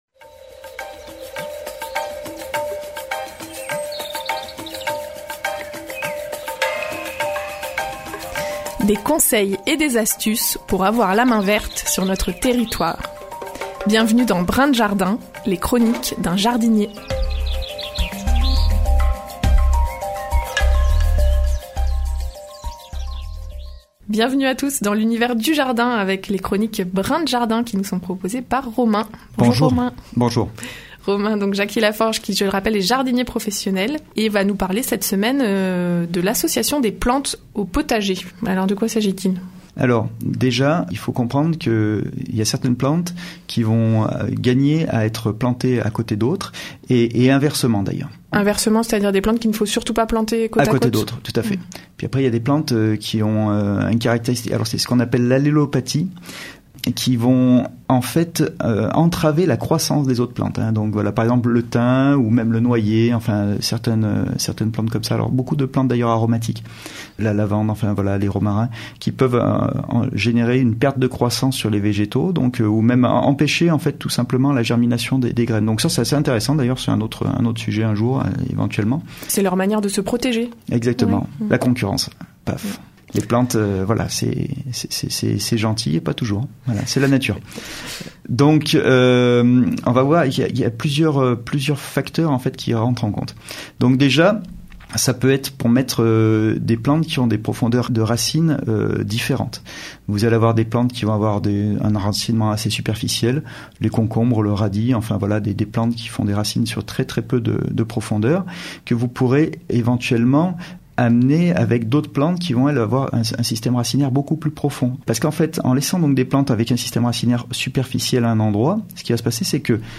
La chronique jardin hebdomadaire sur les ondes de Radio Royans Vercors